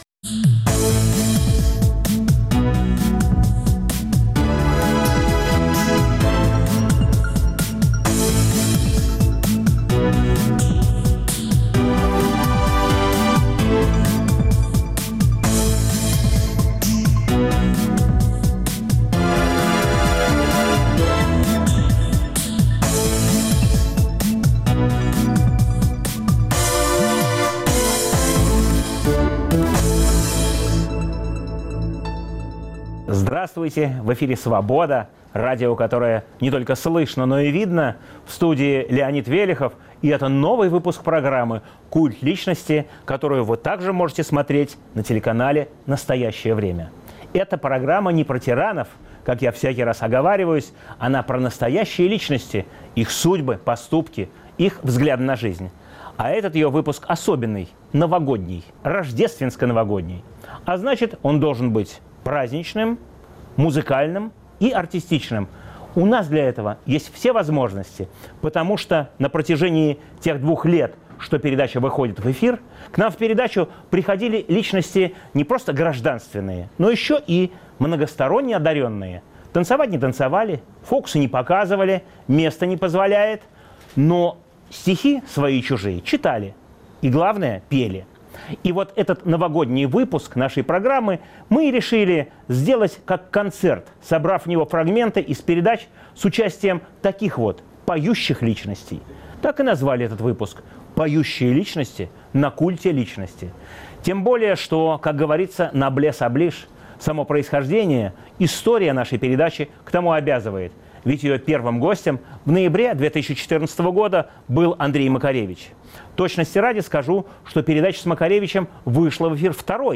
Новогодний концерт по заявкам Радио Свобода с участием